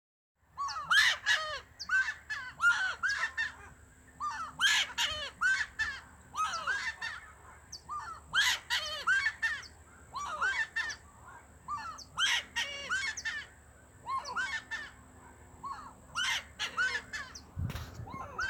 Giant Wood Rail (Aramides ypecaha)
Condition: Wild
Certainty: Observed, Recorded vocal